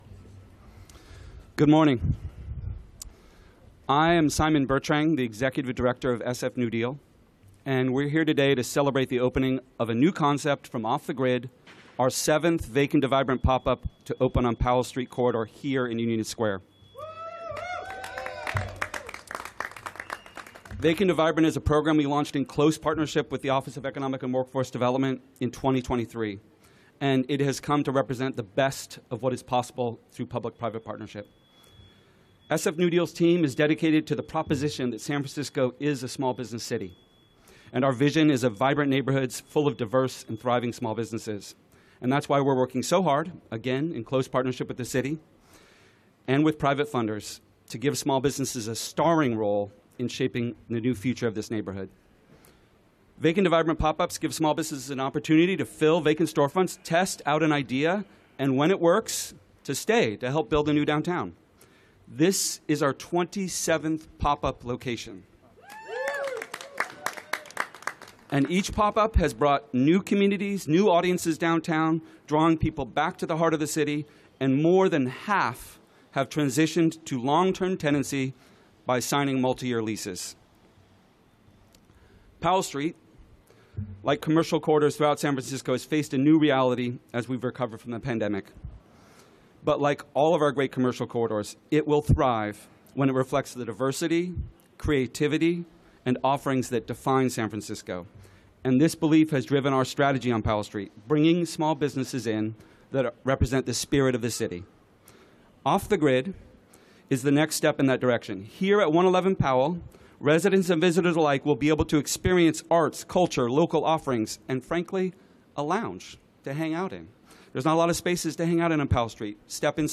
Mayor's Press Conference Audio